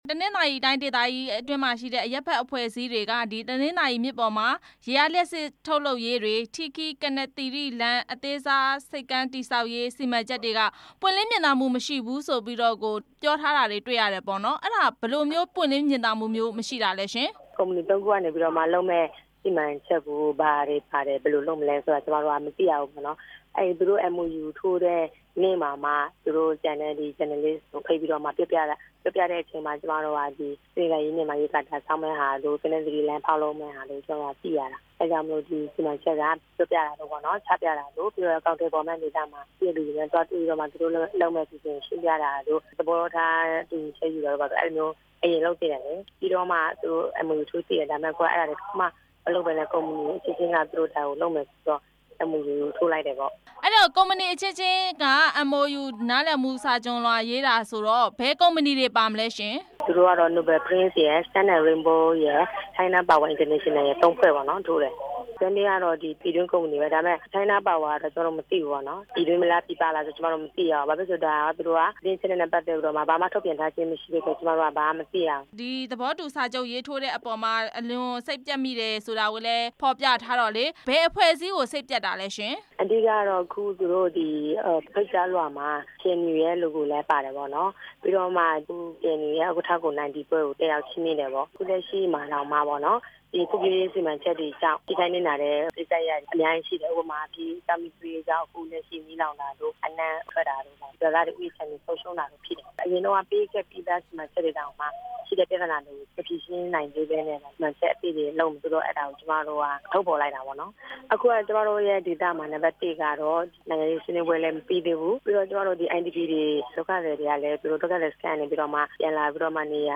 KNU စီးပွားရေးလုပ်ငန်းတွေကို အရပ်ဘက်အဖွဲ့ ကန့်ကွက်တဲ့အကြောင်း မေးမြန်းချက်